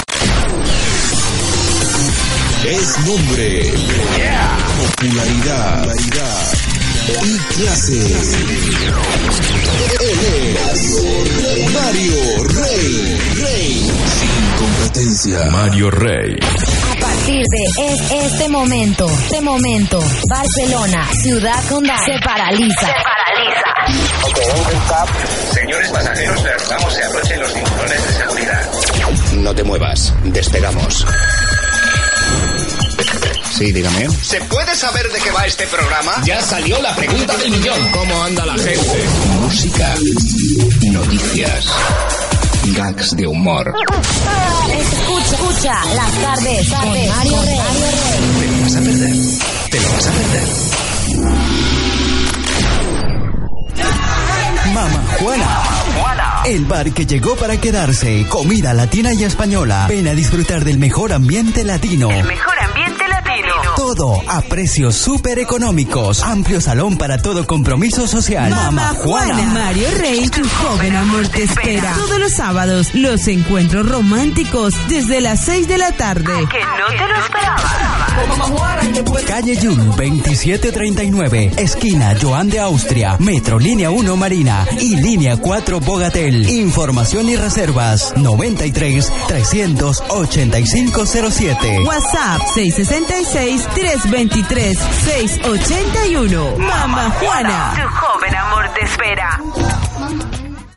Indicatiu del programa i publicitat
Entreteniment